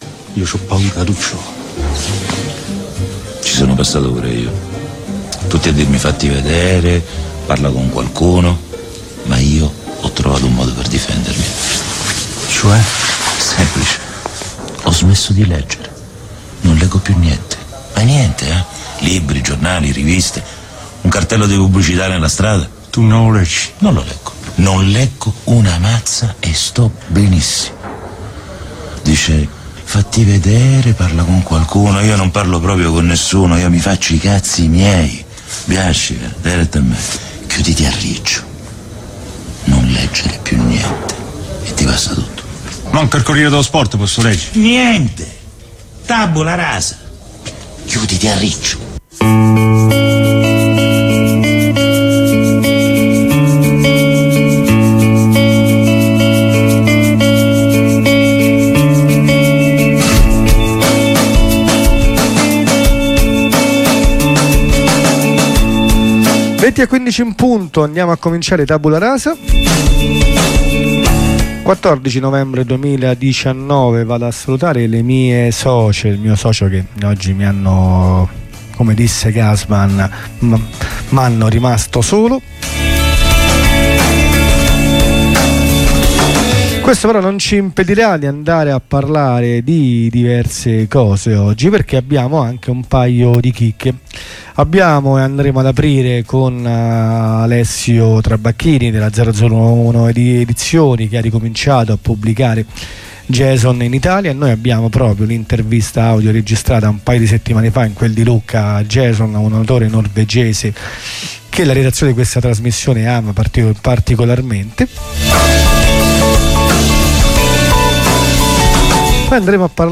La trasmissione che legge i libri per voi.